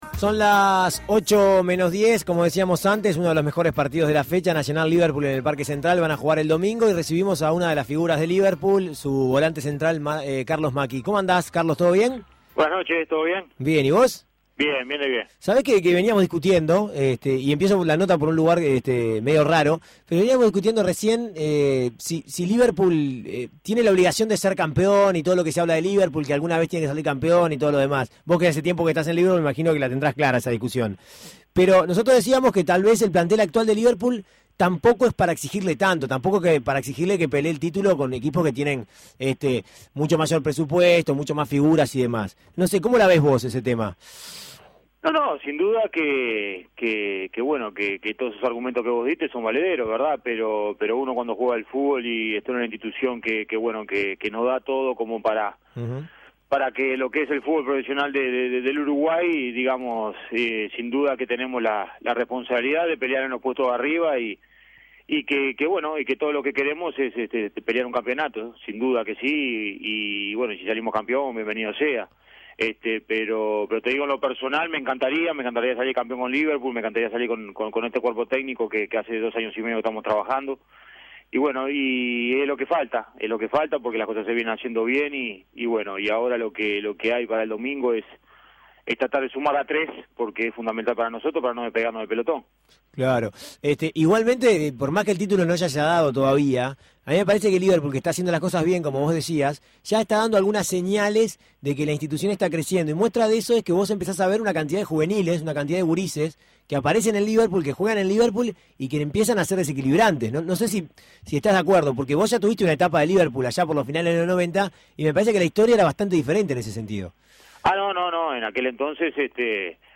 Escuche la nota al futbolista de Liverpool